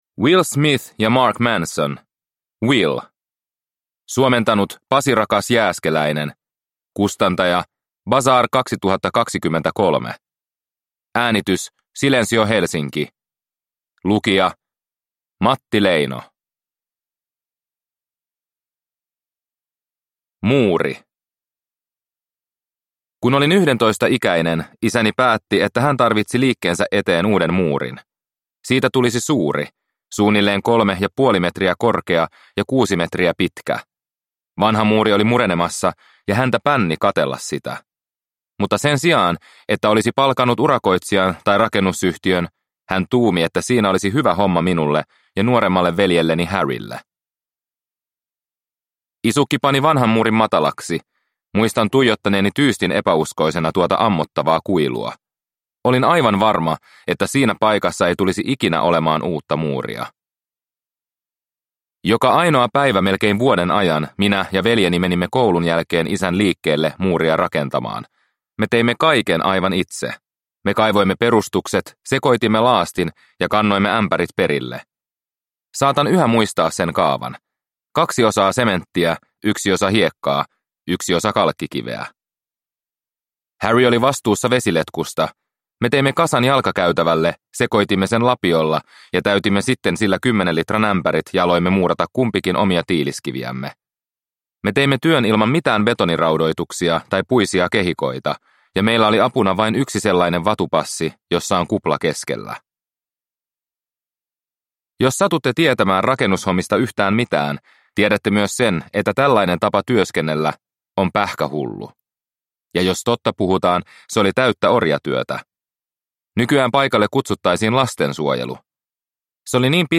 Will – Ljudbok